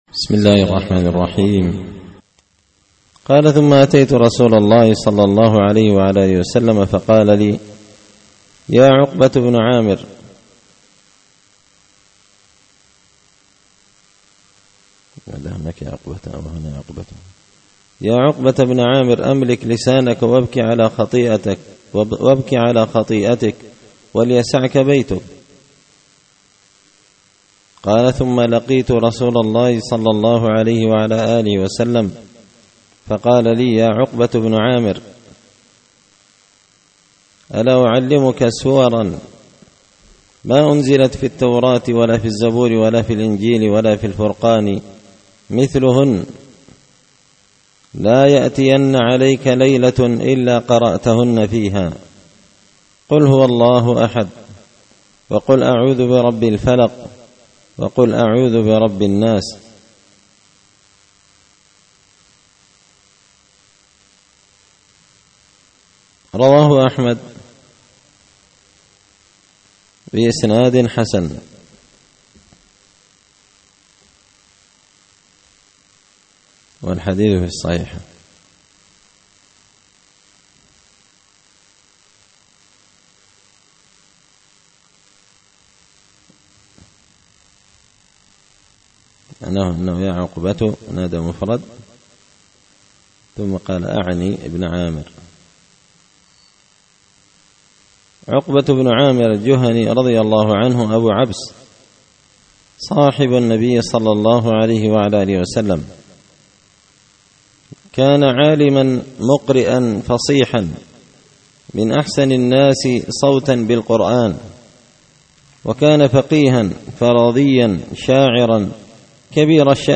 الأحاديث الحسان فيما صح من فضائل سور القرآن ـ الدرس الخامس والخمسون
دار الحديث بمسجد الفرقان ـ قشن ـ المهرة ـ اليمن